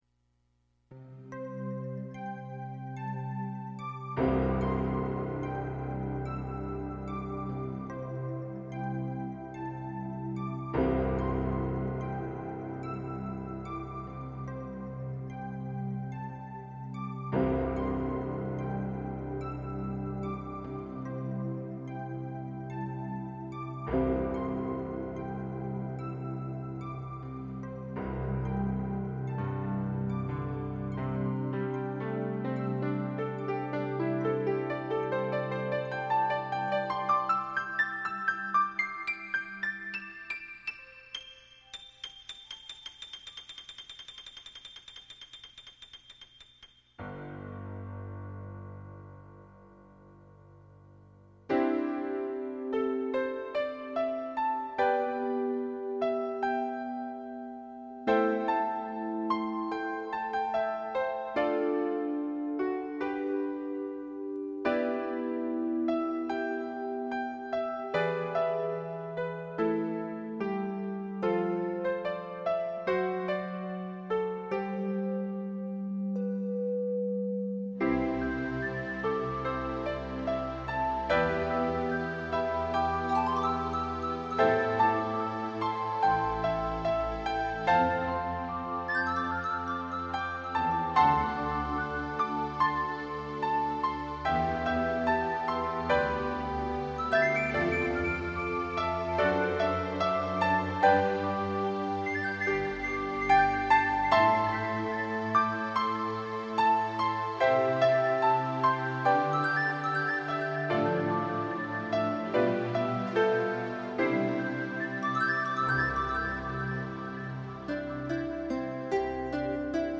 以獨特的新樂風織就心靈清美情境. 鋼琴的清柔襯上MIDI的多變化，在古典中開創出一條新路。